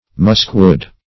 Muskwood \Musk"wood`\, n. [So called from its fragrance.]